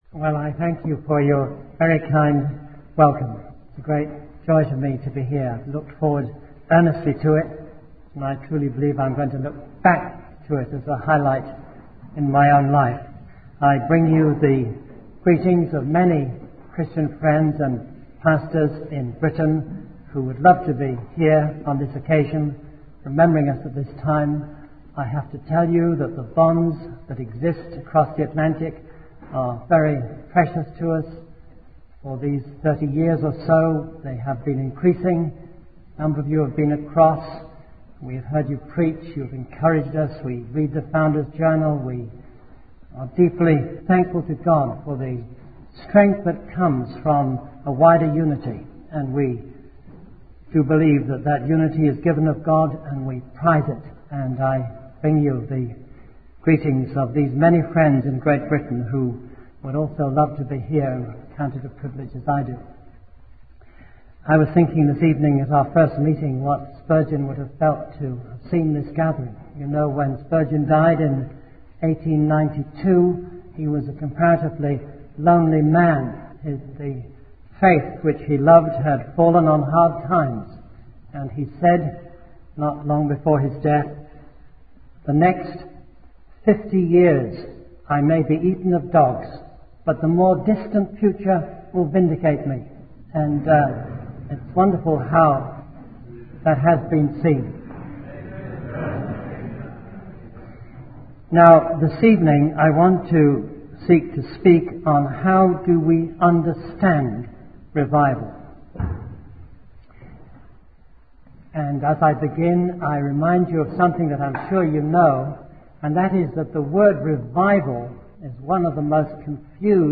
In this sermon, the speaker discusses the concept of revival and its connection to the preaching of the word of God. He emphasizes the importance of both evangelism and personal repentance and holiness in bringing about revival.